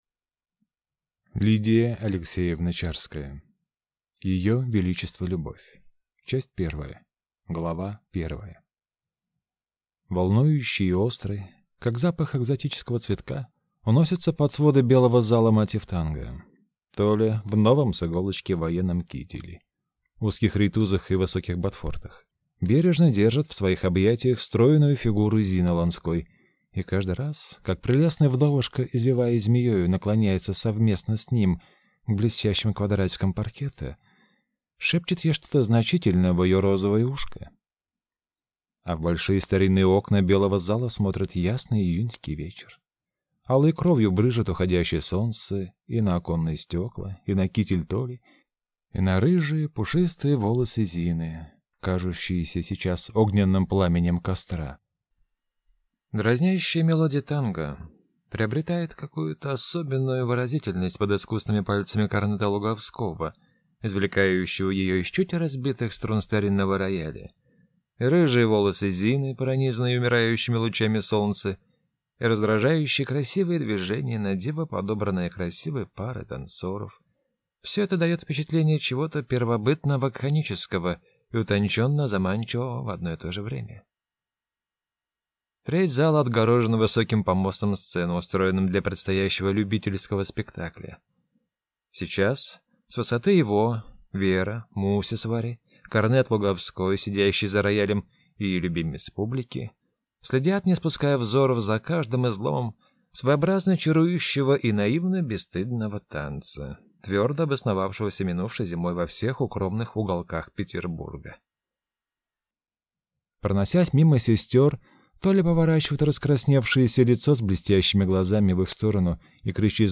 Аудиокнига Ее величество Любовь | Библиотека аудиокниг